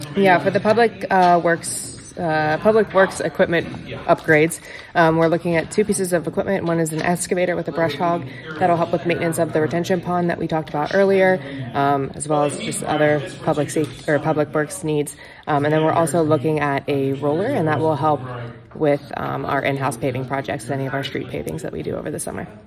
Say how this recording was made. At their meeting Tuesday night, Indiana Borough Council approved applications for three grants.